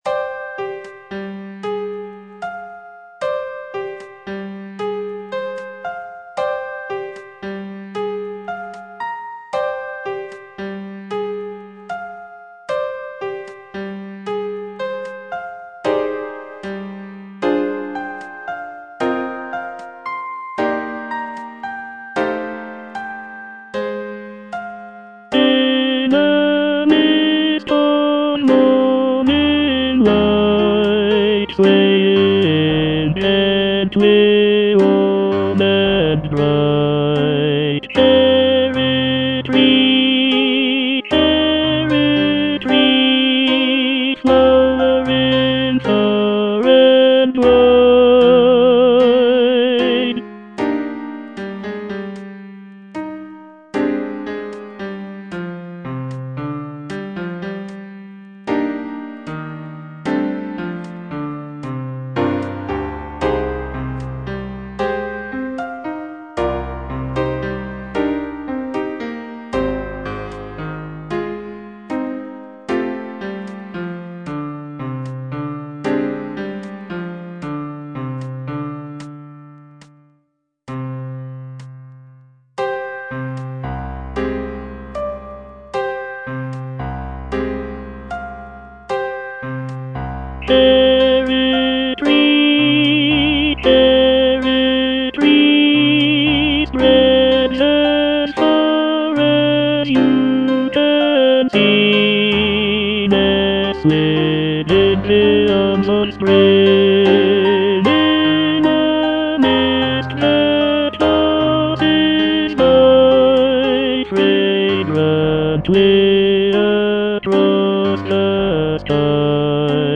Tenor (Voice with metronome) Ads stop